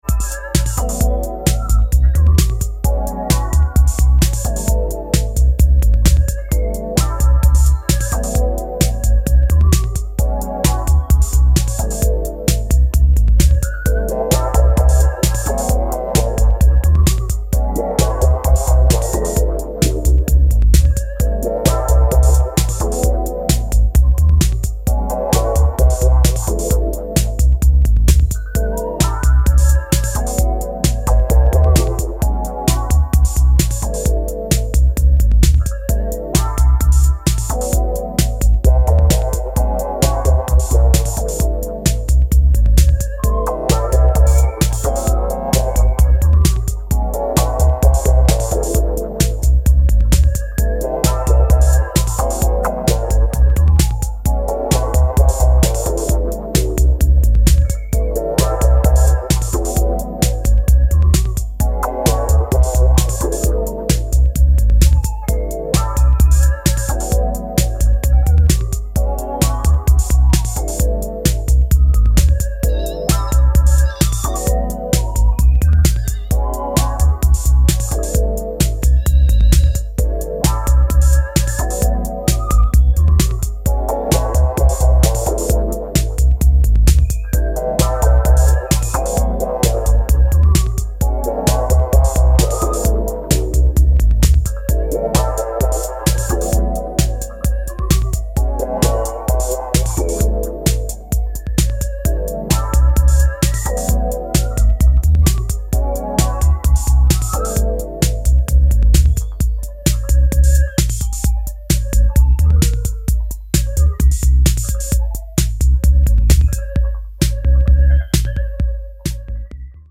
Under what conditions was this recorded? Remastered from the original session tapes.